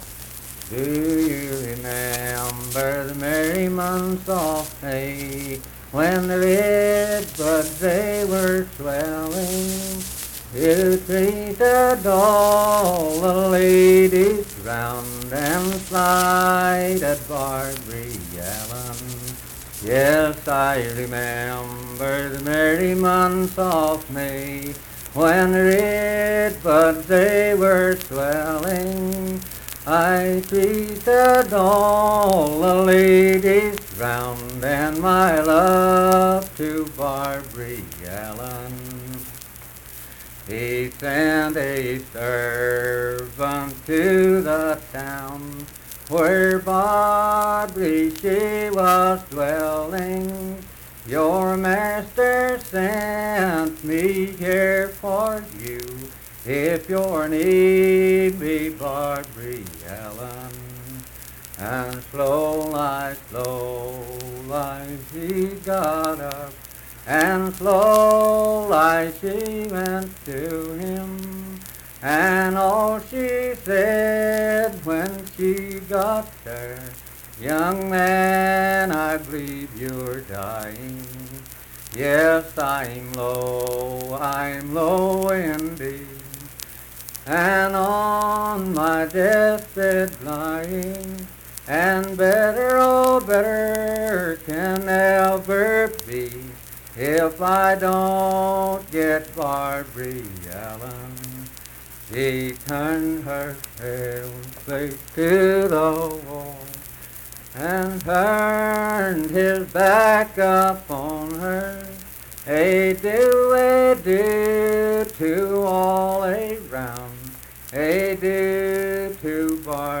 Unaccompanied vocal music performance
Verse-refrain 11(4).
Performed in Kliny, Pendleton County, WV.
Voice (sung)